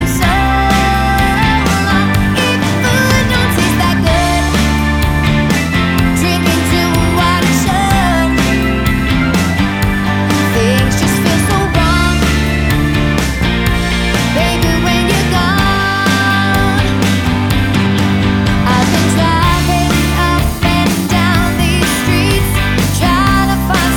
Duet Version Duets 3:21 Buy £1.50